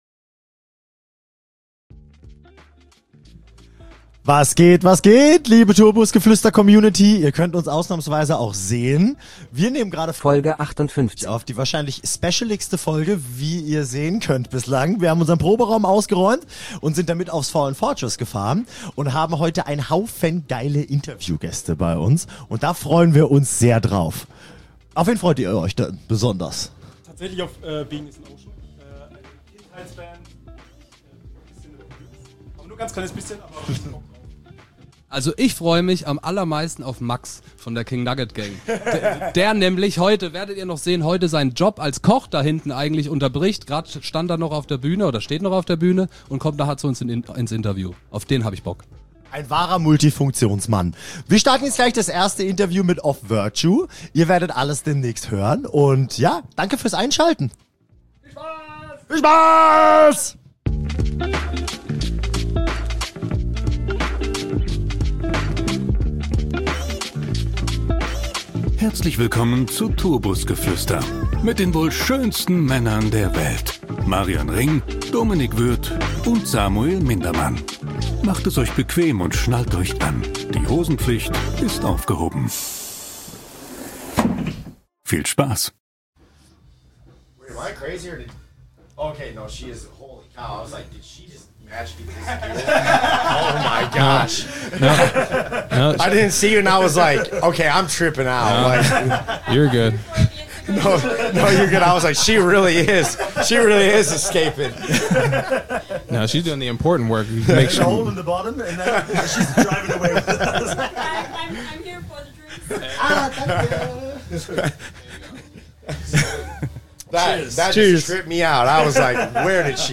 Interviews beim Fallen Fortress 2023